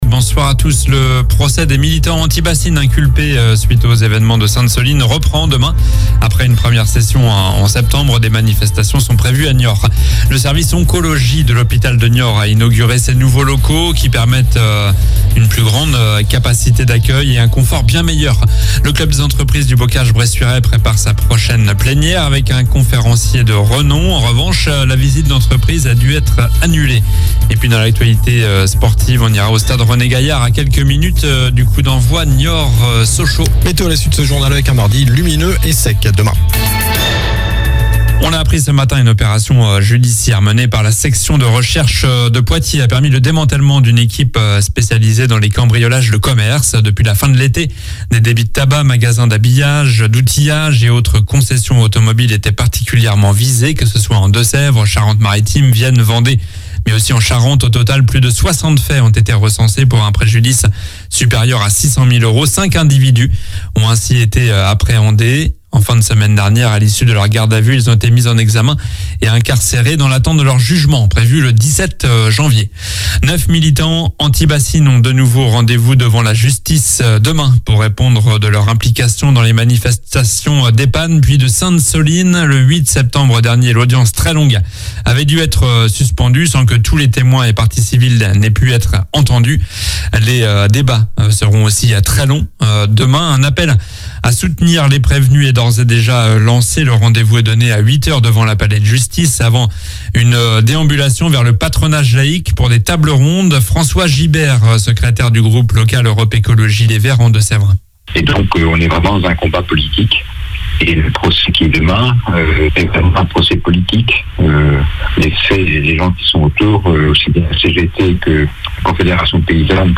Journal du lundi 27 novembre (soir)